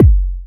Index of /90_sSampleCDs/Sound & Vision - Gigapack I CD 1 (Roland)/KIT_DANCE 1-16/KIT_Dance-Kit 15
BD BD084.wav